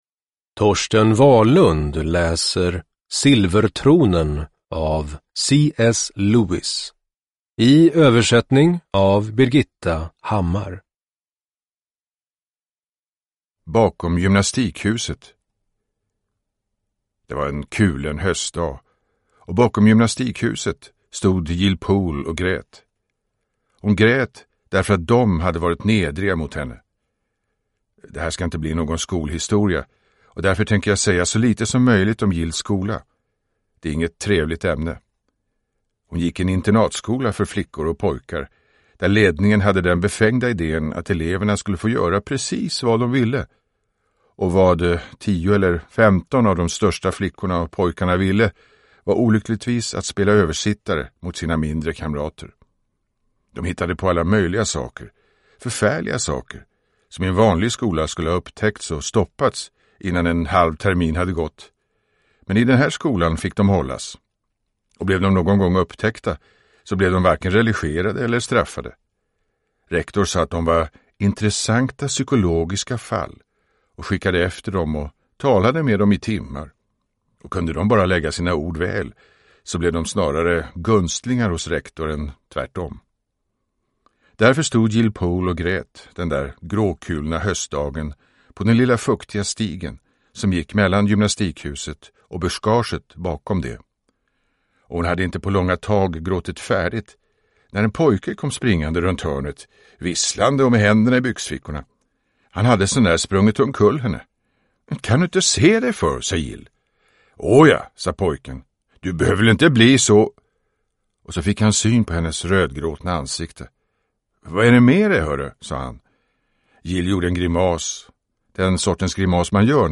Silvertronen – Ljudbok – Laddas ner
Uppläsare: Torsten Wahlund